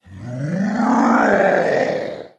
One of Bowser's voice clips in Mario & Luigi: Brothership
BrothershipBowserGrowl.oga.mp3